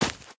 t_grass4.ogg